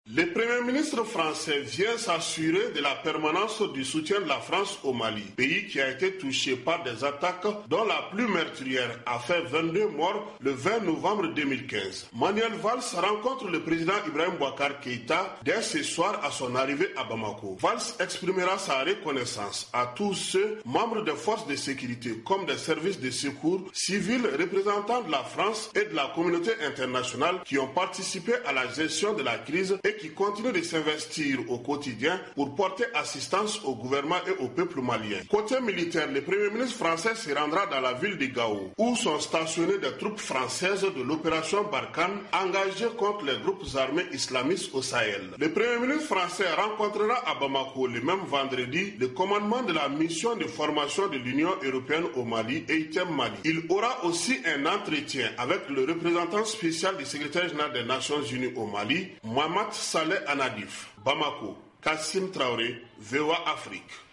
De Bamako, la correspondance